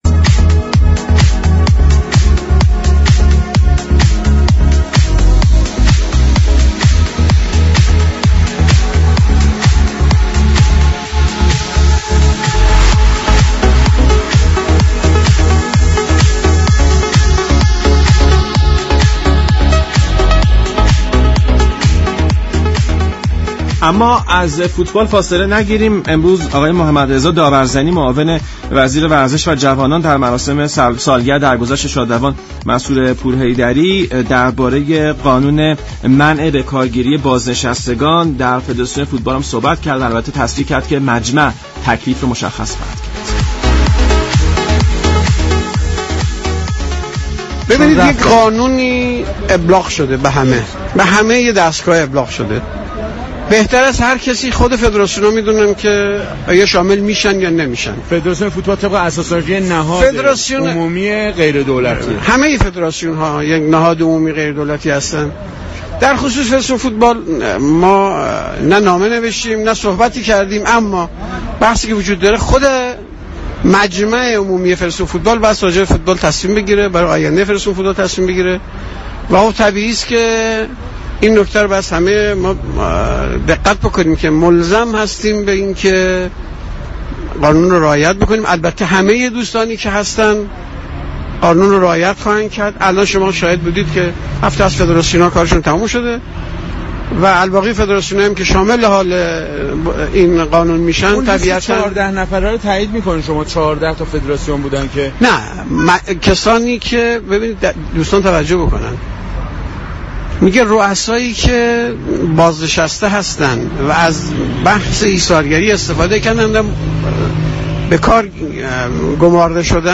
محمدرضا داورزنی معاون وزیر ورزش و جوانان در گفت و گو با برنامه «ورزش ایران» درباره جزئیات قانون منع بازنشستگان در فدراسیون فوتبال گفت: اگرچه تمامی فدراسیون ها جزو نهادهای غیر دولتی محسوب می شوند و در این ارتباط میان دولت و فدراسیون فوتبال هیچ نوع مكاتباتی صورت نگرفته اما اجرا یا عدم اجرای این قانون در فدراسیون فوتبال، بسته به رای و نظر مجمع عمومی است.